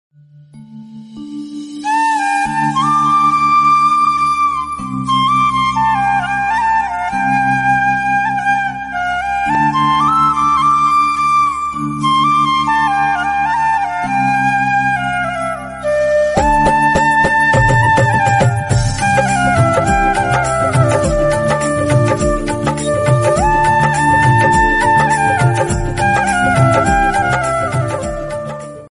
Bhakti Ringtones